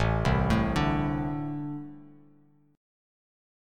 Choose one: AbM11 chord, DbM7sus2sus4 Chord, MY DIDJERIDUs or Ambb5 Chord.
Ambb5 Chord